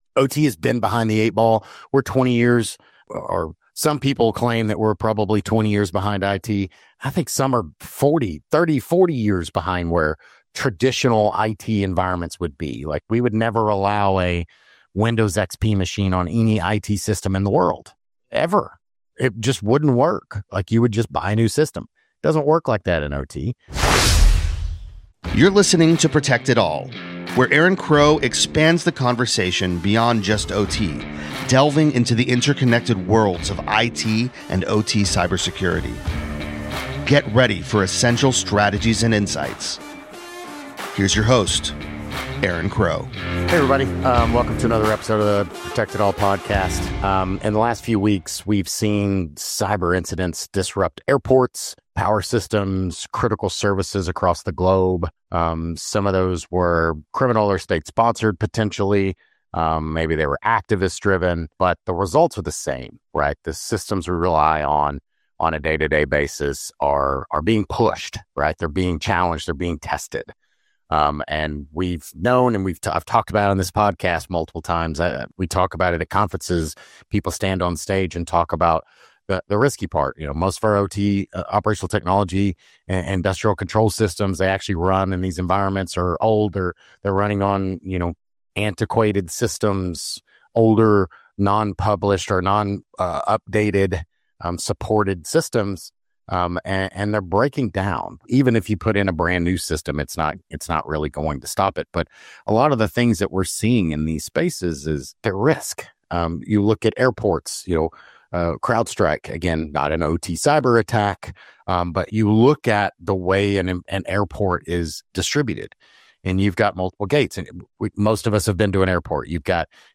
In this solo episode of Protect It All